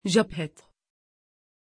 Pronunciation of Japheth
pronunciation-japheth-tr.mp3